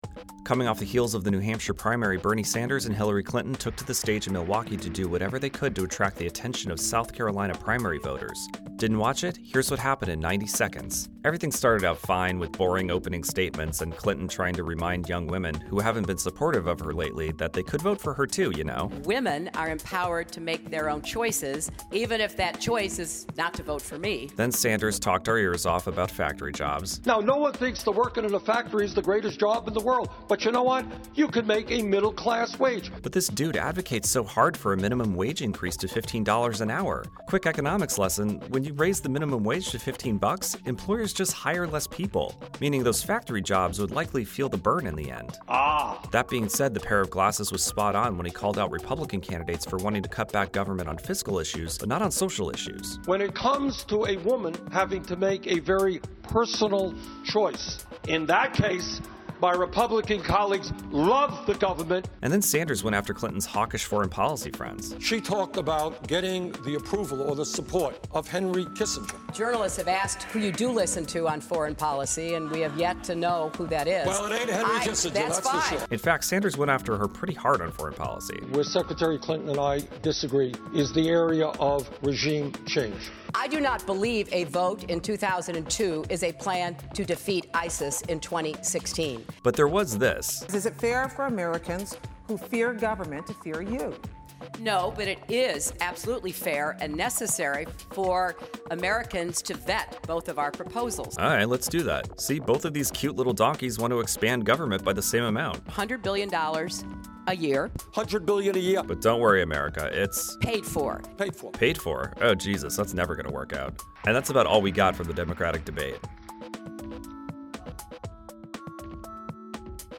Last night, Bernie Sanders and Hillary Clinton took to the stage in Milwaukee to do whatever they could to attract the attention of Demoratic Party primary voters.